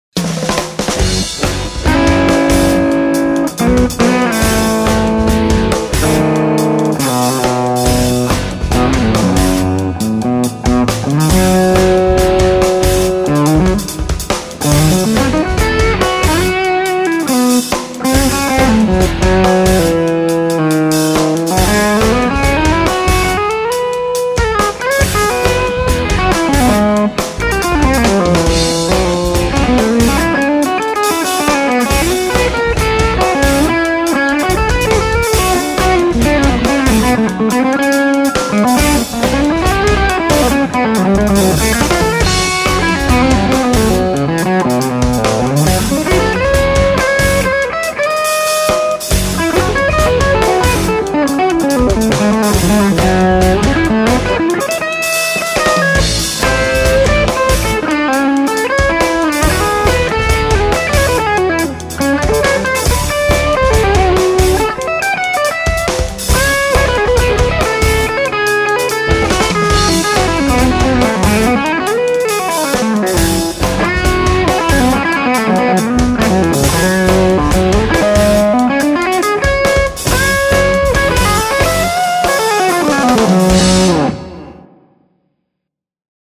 G1265, B1 on bridge, Dumbleator in, no PAB.
Amazing texture in lows and mids. Really sings!
That amp is very smooth sounding throughout the range. It has sort of an Oboe or other woodwind quality about the sound when you get down in the lower registers, something that sets it apart from some other clones.
It seems a bit understated at the moment, not muddy, just mellow.